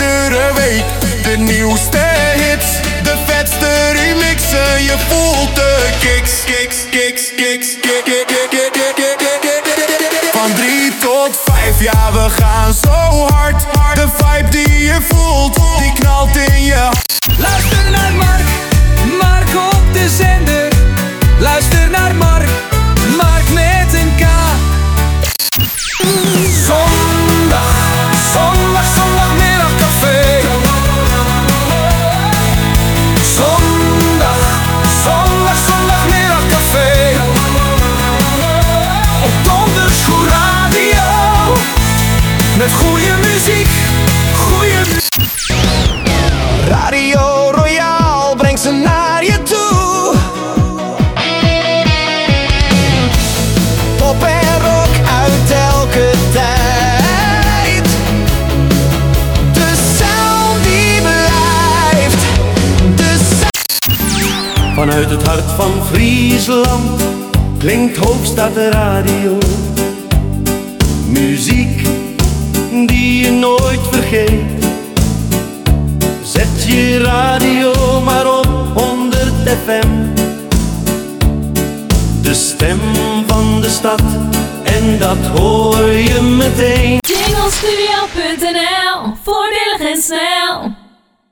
Ai song demo